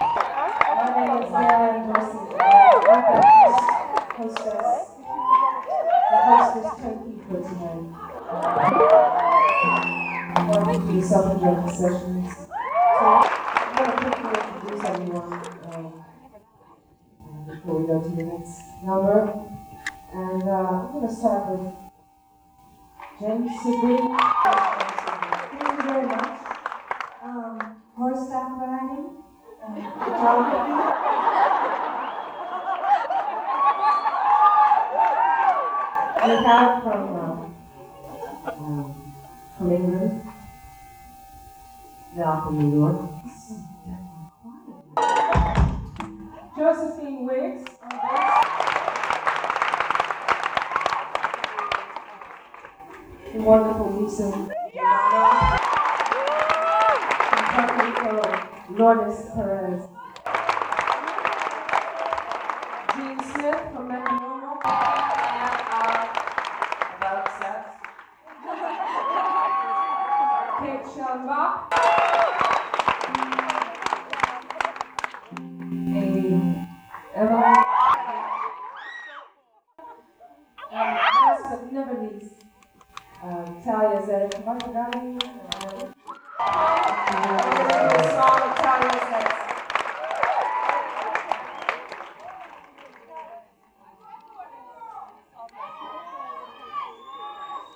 lifeblood: bootlegs: 1998-08-26: park west - chicago, illinois (suffragette sessions tour)
02. talking with the crowd (1:37)